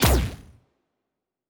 pgs/Assets/Audio/Sci-Fi Sounds/Weapons/Weapon 03 Shoot 1.wav at master
Weapon 03 Shoot 1.wav